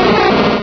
pokeemerald / sound / direct_sound_samples / cries / pineco.aif